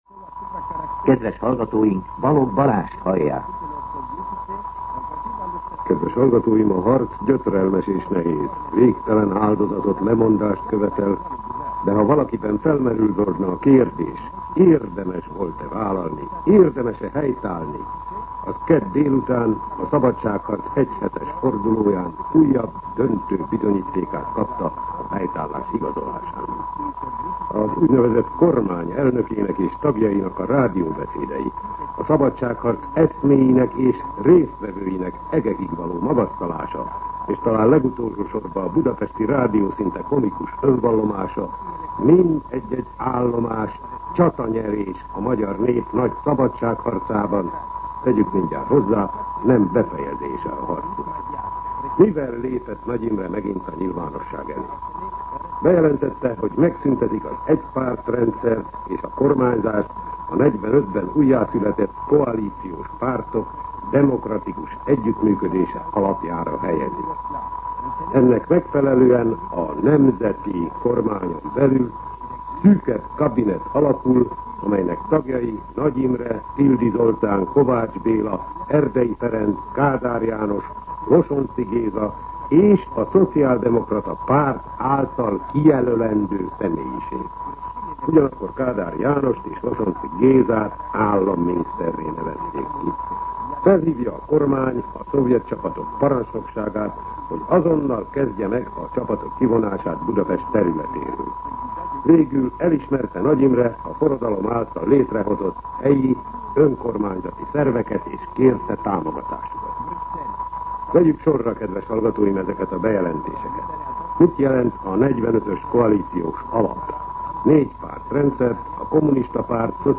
Rendkívüli kommentár